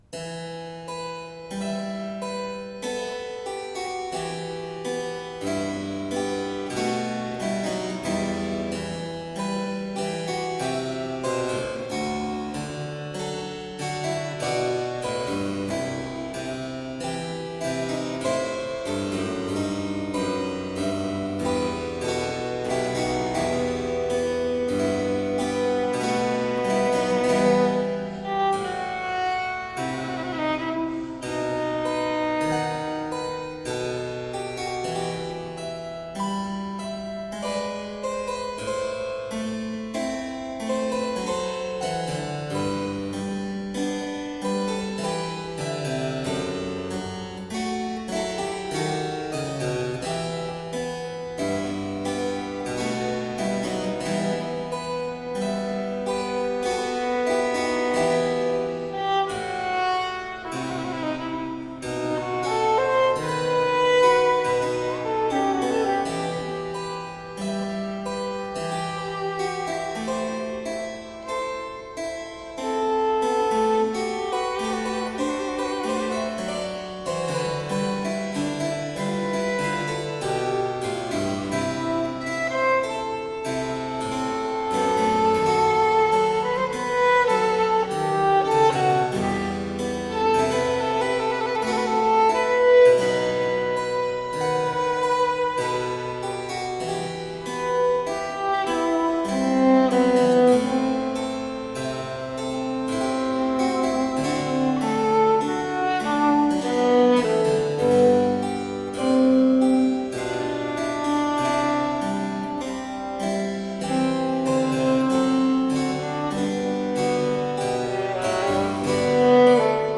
Baroque violin & harpsichord.